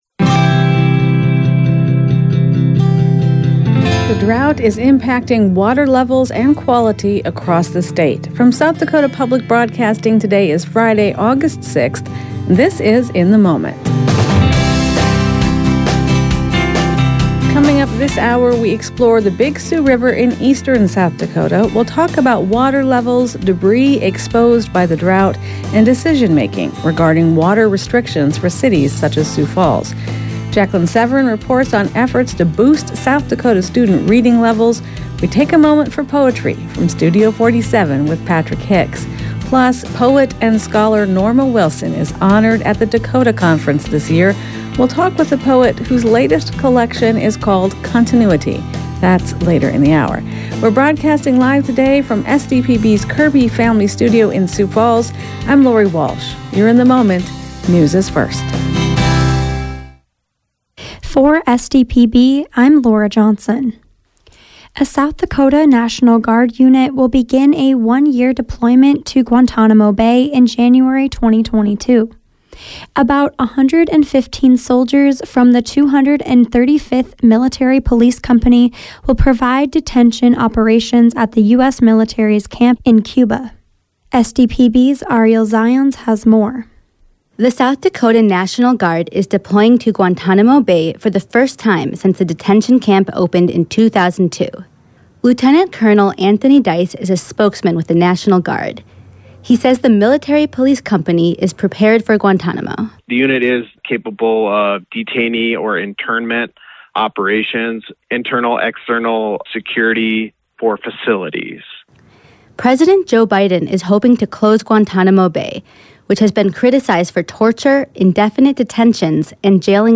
In the Moment is SDPB’s daily news and culture magazine program.